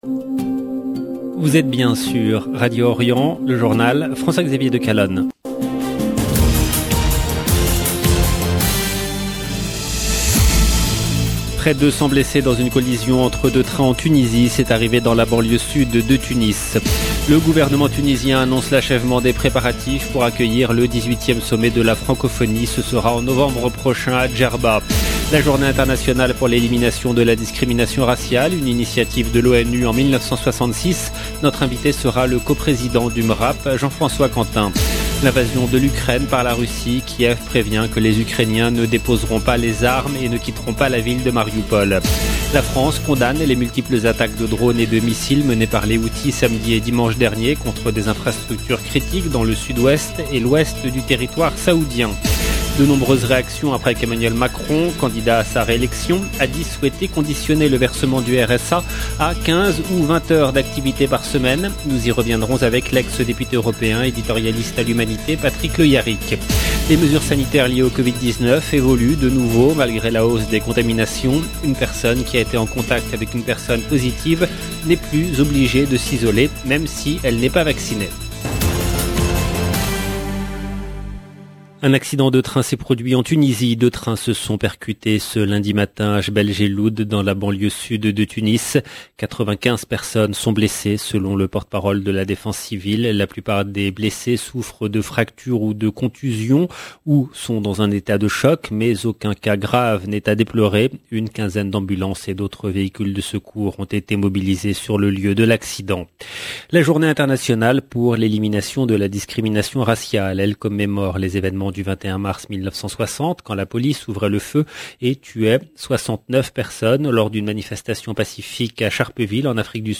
LE JOURNAL DU SOIR EN LANGUE FRANCAISE DU 21/03/22